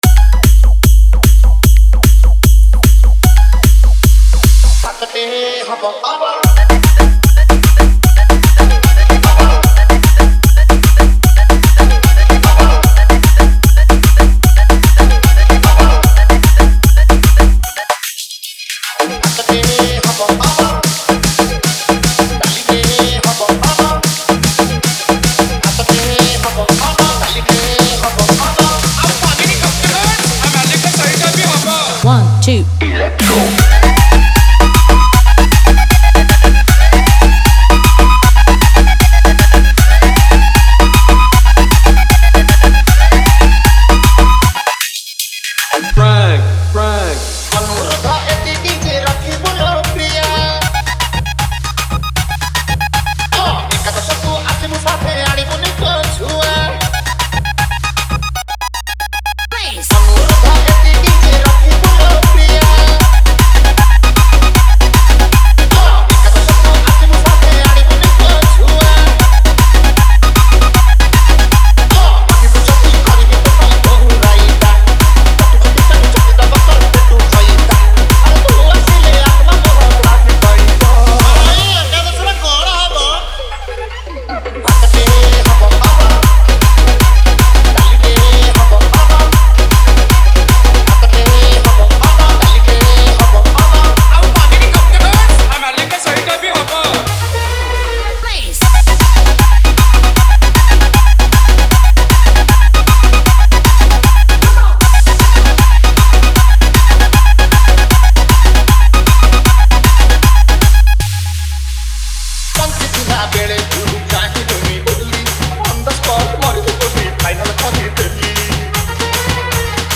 TRANCE VIBRATE